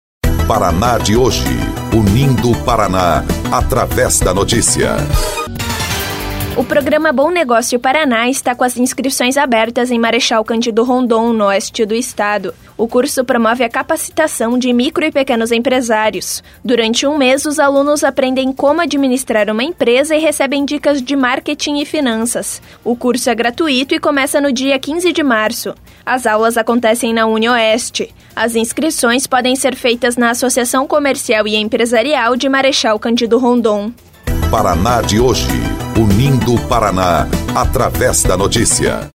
BOLETIM – Curso gratuito capacita empresários em marechal Cândido Rondon